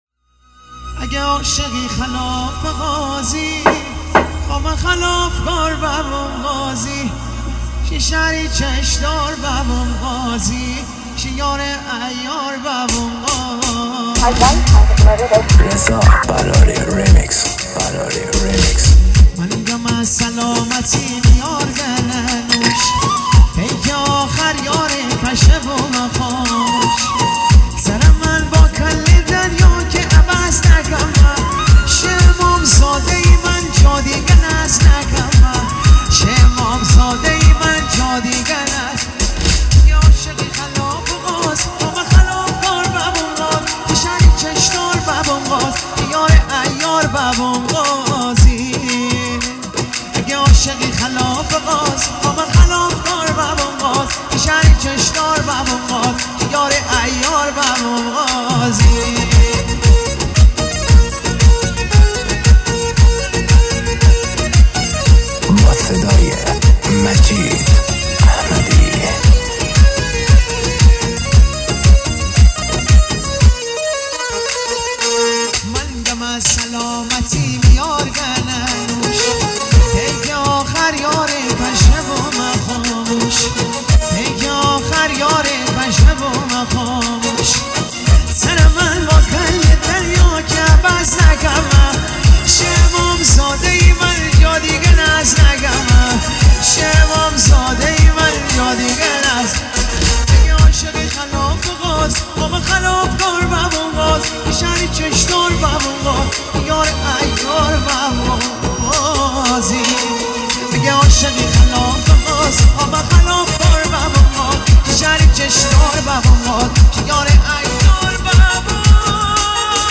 پاپ
آهنگ لاتی